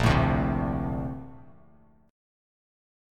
A#M9 chord